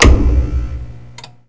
power_switch.wav